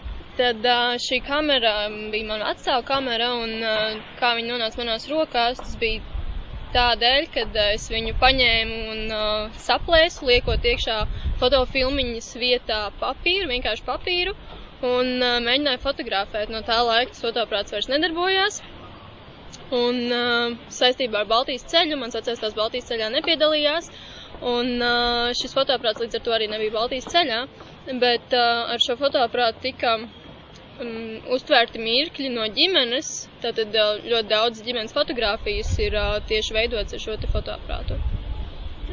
Atmiņu stāsts ierakstīts Eiropas digitālās bibliotēkas "Europeana" un Latvijas Nacionālās bibliotēkas organizētajās Baltijas ceļa atceres dienās, kas notika 2013. gada 23. un 24. augustā Rīgā, Esplanādē.